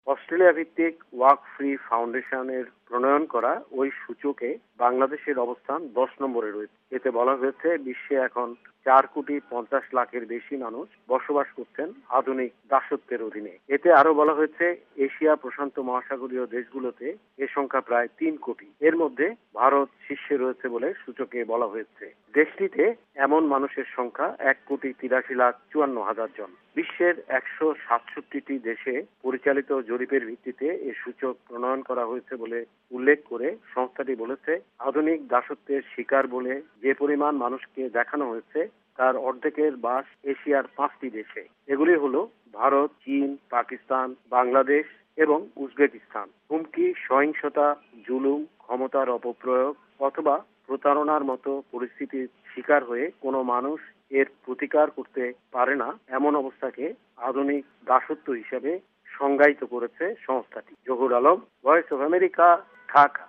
Dhaka, Bangladesh